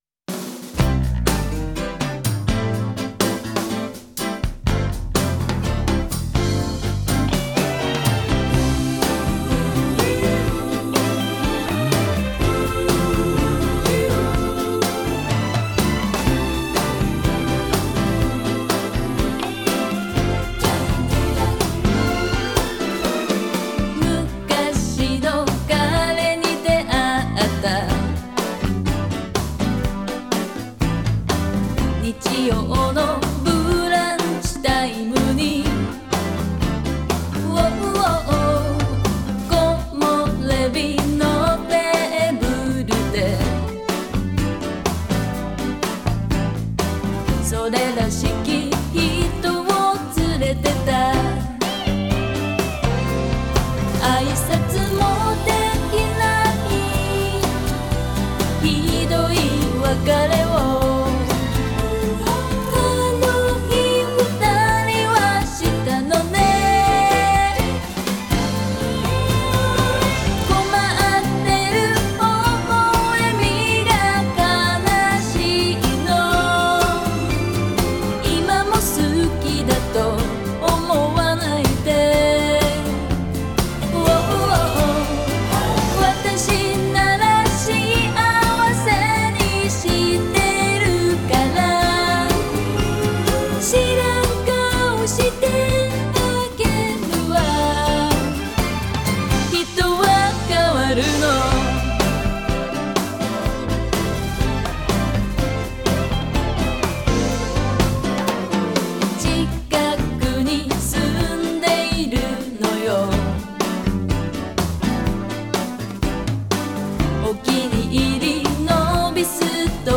Genre: DOMESTIC(J-POPS).